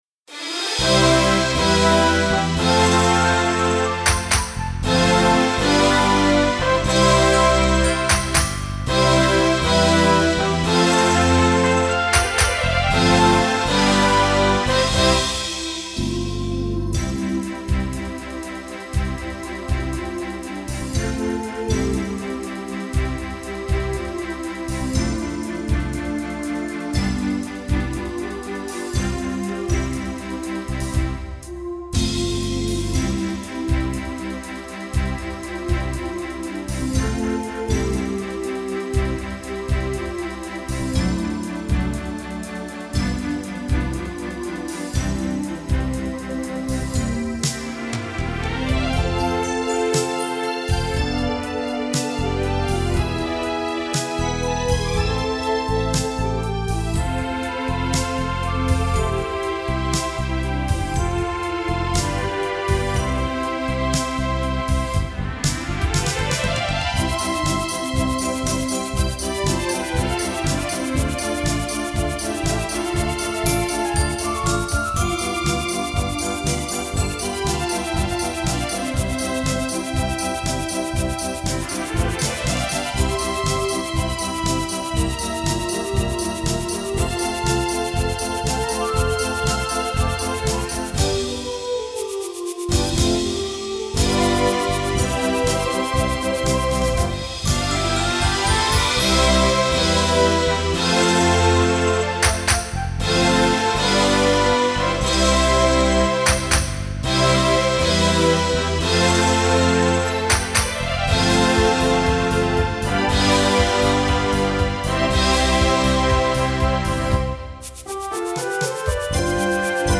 2015年3月11日　東亜学園ホールにて録音
カラオケ（音楽ファイル(MP3)：9,742KB）